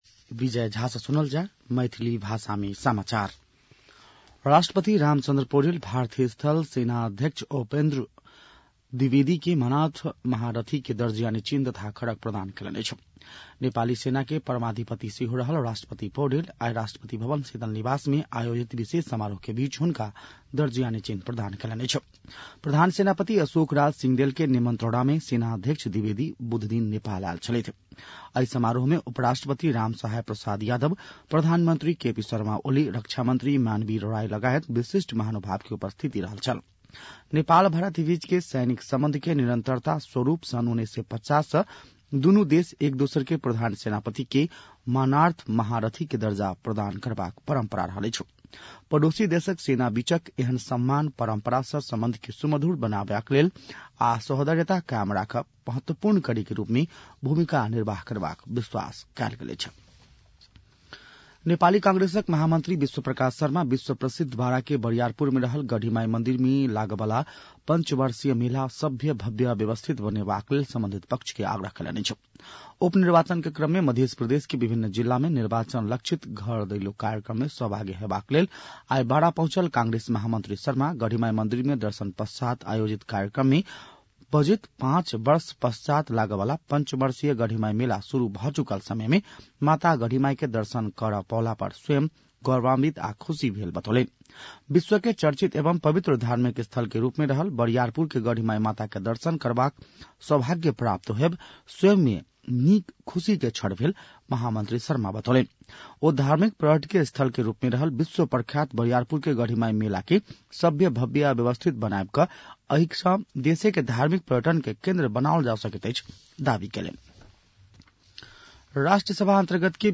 मैथिली भाषामा समाचार : ७ मंसिर , २०८१
Maithali-news-8-6.mp3